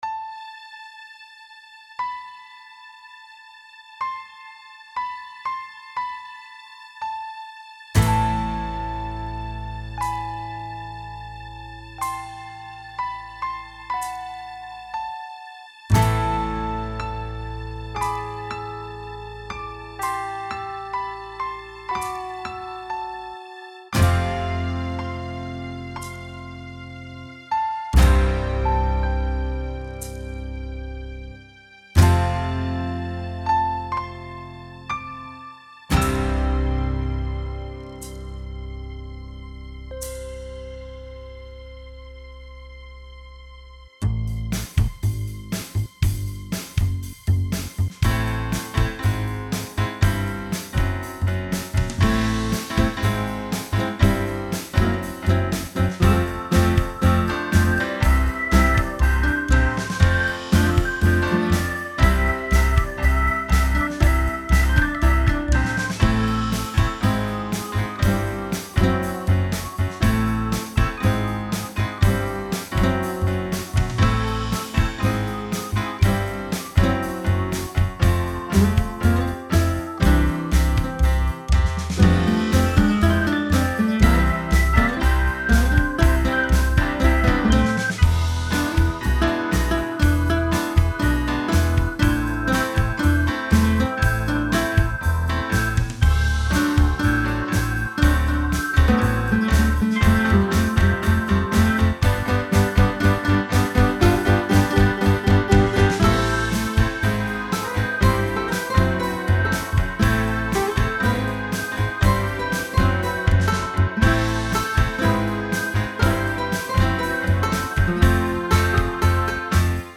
instrumental arrangement
Orchestral Samples
Guitars, Strings Section, Piano, Brass Section, Bass,
Percussion and Drums